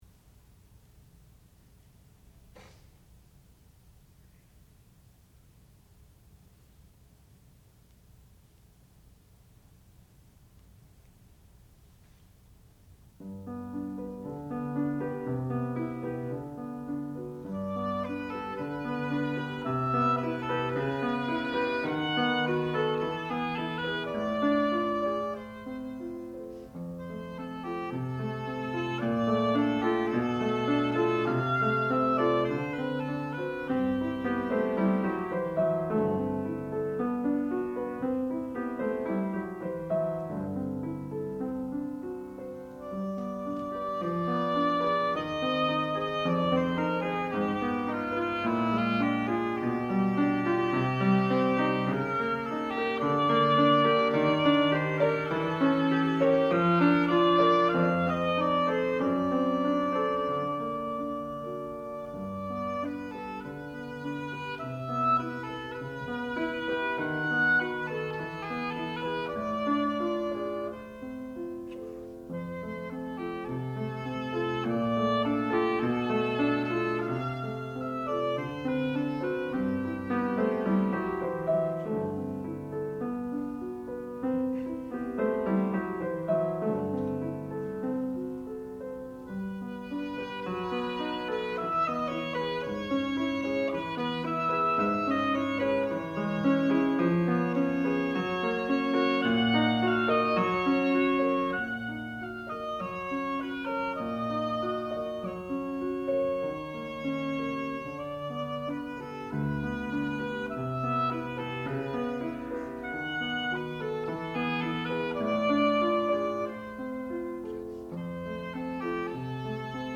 sound recording-musical
classical music
piano
oboe